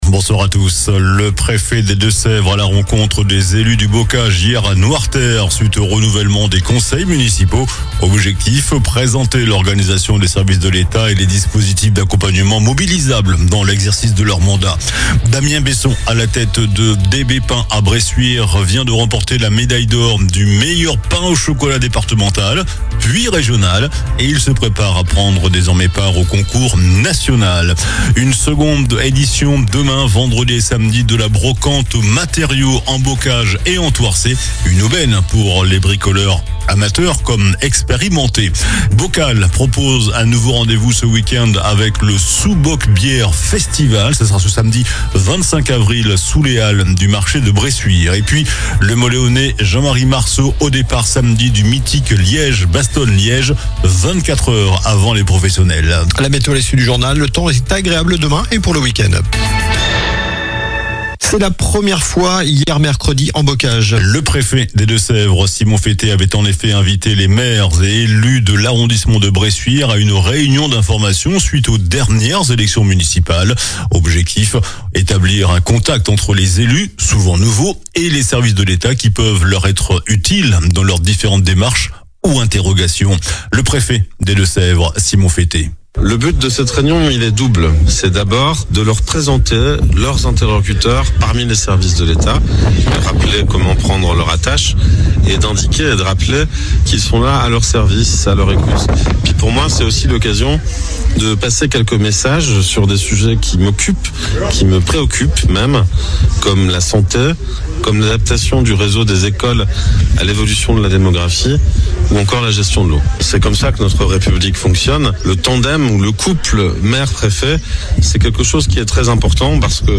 JOURNAL DU JEUDI 23 AVRIL ( SOIR )